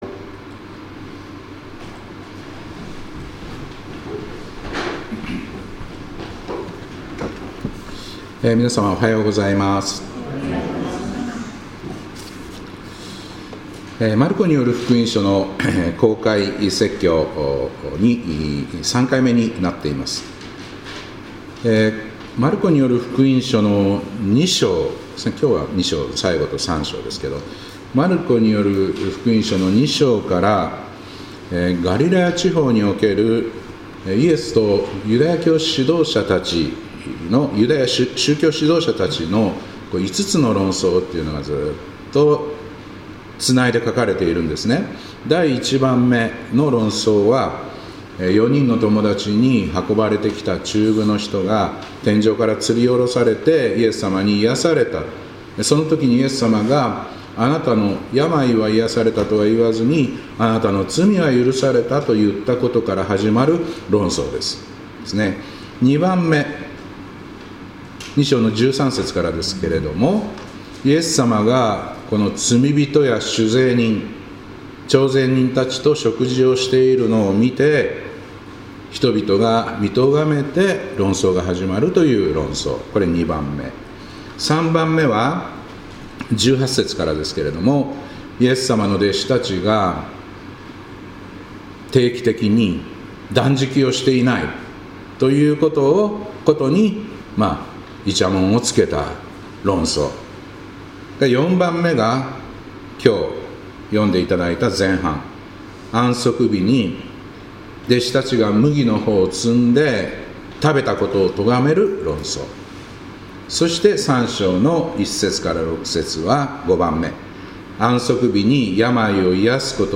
2026年1月18日礼拝「安息日の真ん中」